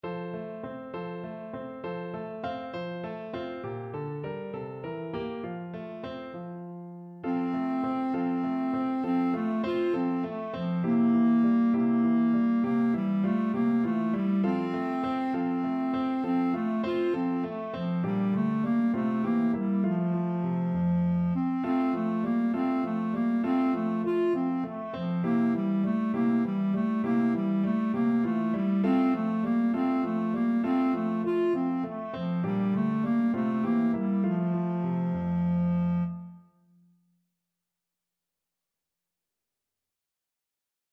Clarinet version
6/8 (View more 6/8 Music)
Moderato
Traditional (View more Traditional Clarinet Music)